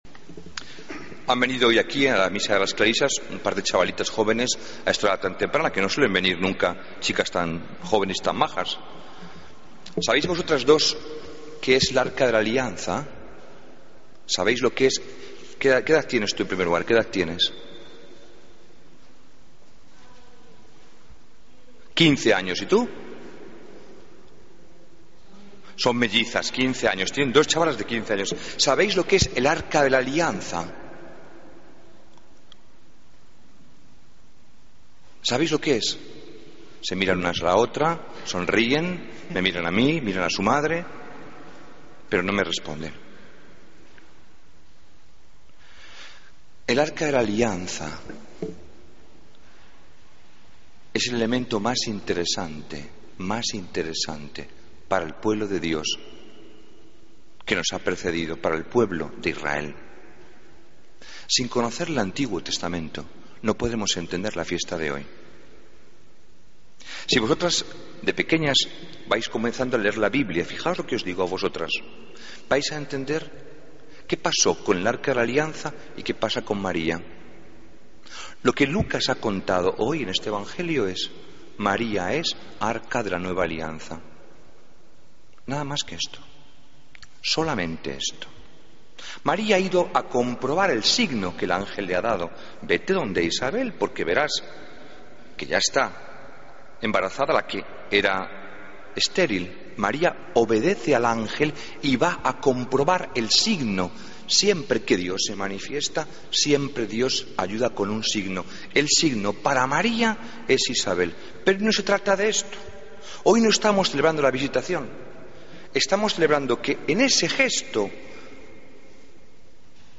Homilía del 15 de agosto